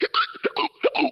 Hiccuping man sound effect
Cartoon Hiccups Vocal sound effect free sound royalty free Voices